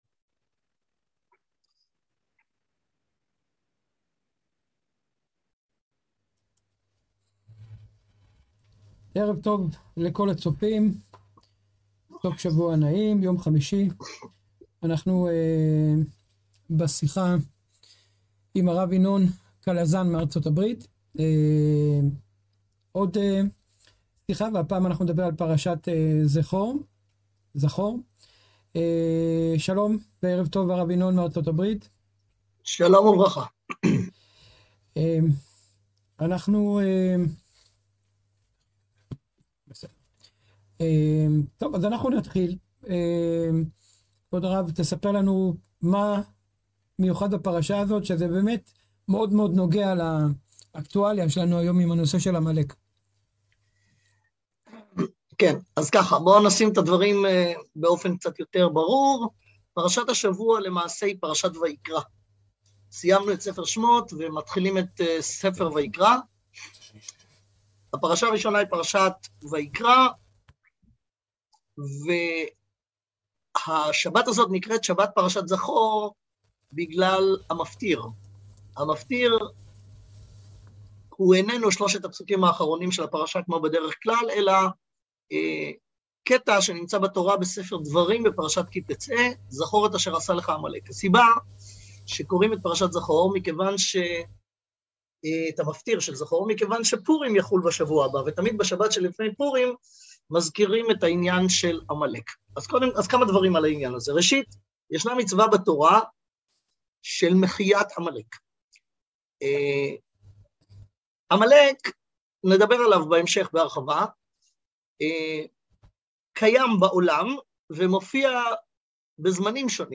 שיחות על פרשת השבוע והשלכות אקטואליות